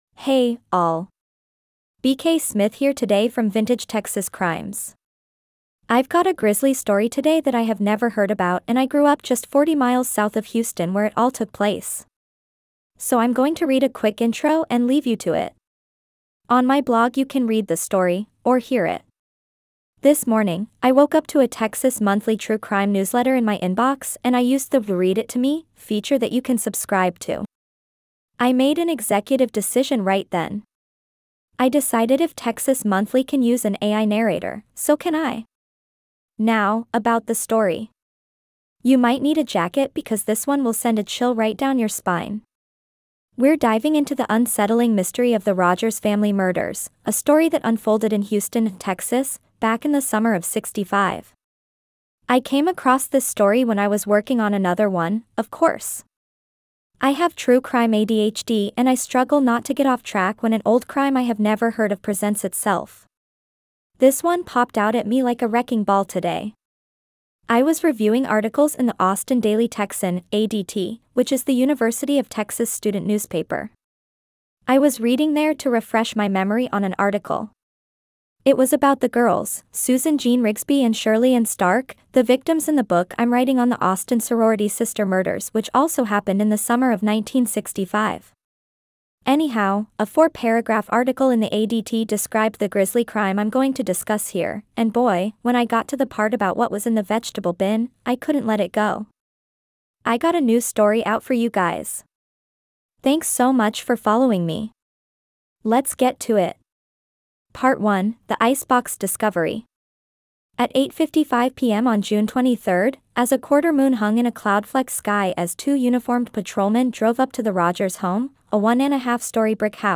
I decided if Texas Monthly can use an AI narrator, so can I.